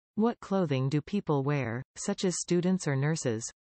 You will hear a question.